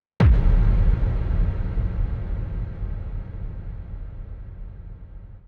SouthSide Stomp (8) .wav